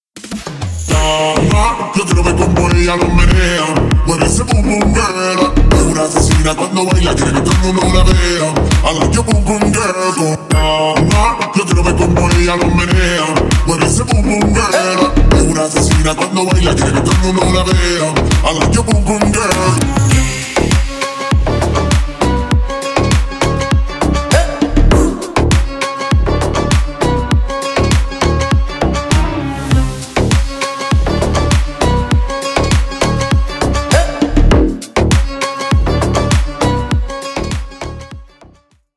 Ремикс # Танцевальные
латинские